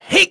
Neraxis-Vox_Attack1.wav